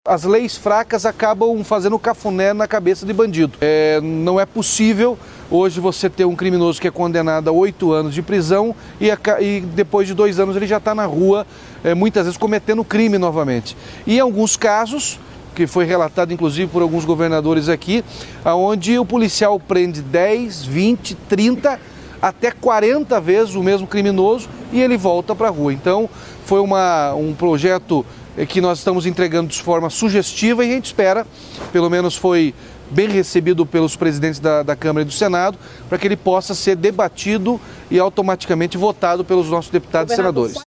Sonora do governador Ratinho Junior sobre a entrega de propostas à União e Congresso de endurecimento das leis penais